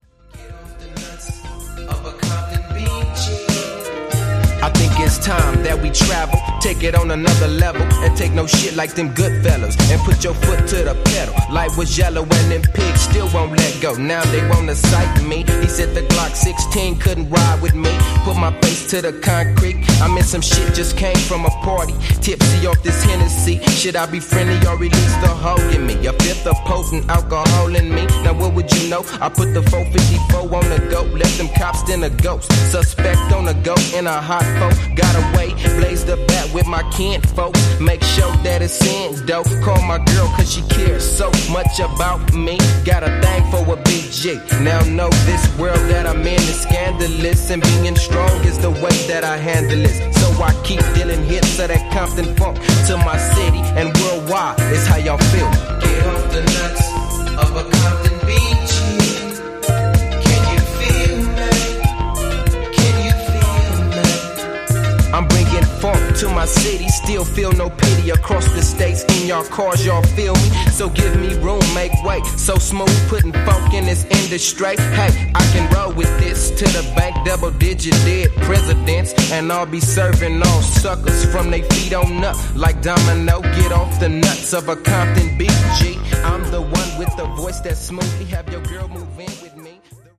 ジャンル(スタイル) RAP / HIP HOP